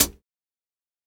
Closed Hats
BattleCatHat.wav